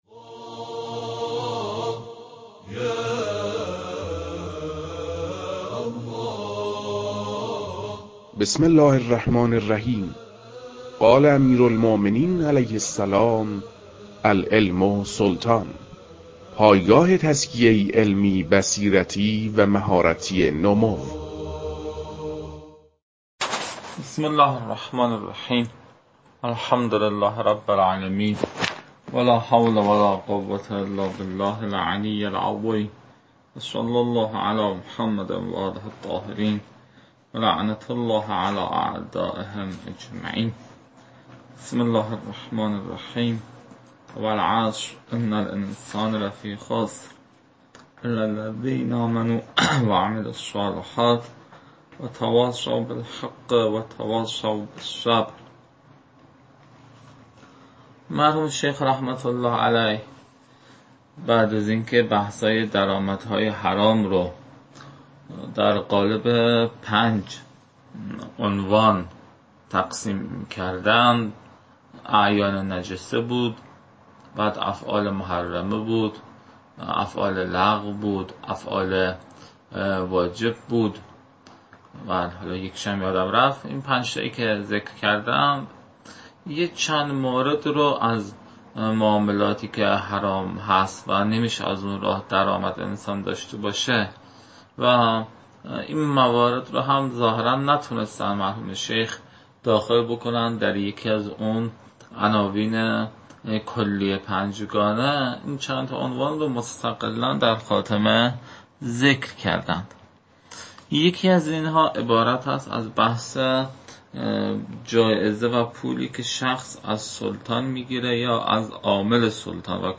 فایل های مربوط به تدریس مبحث المسألة الثانية جوائز السلطان و عمّاله از خاتمه كتاب المكاسب متعلق به شیخ اعظم انصاری رحمه الله